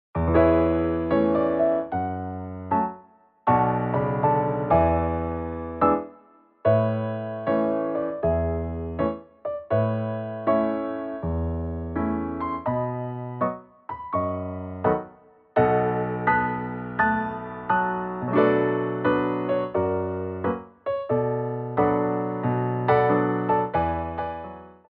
CHANGE OF TEMPO